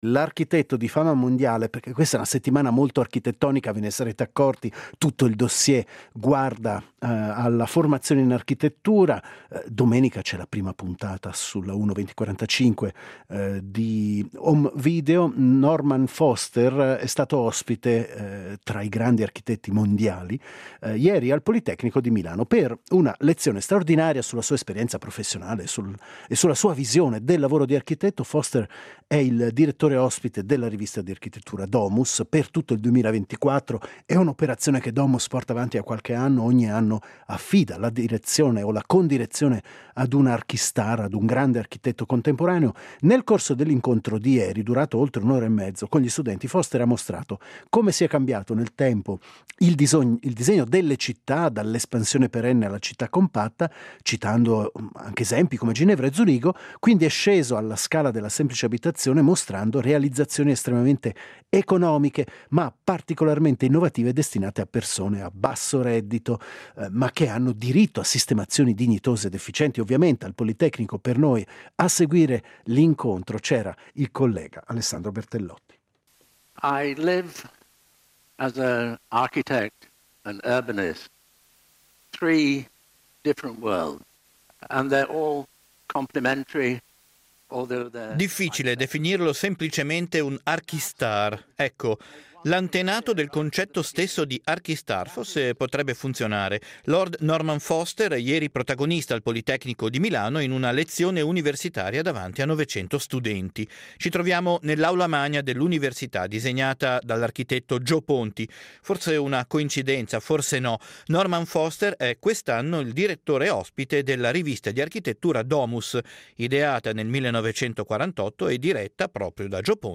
Incontro con l’archistar Norman Foster